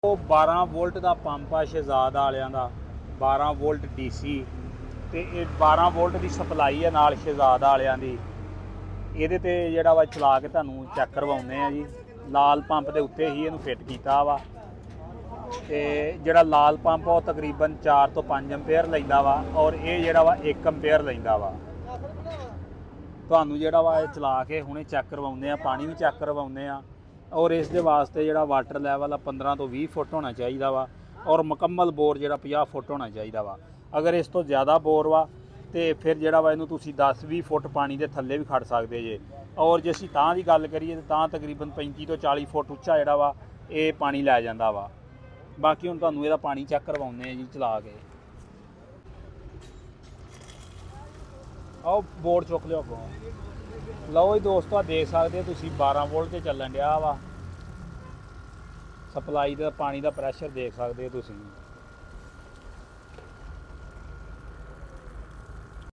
12v Dc Shahad Pump Fitaing Sound Effects Free Download